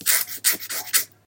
scratch.ogg.mp3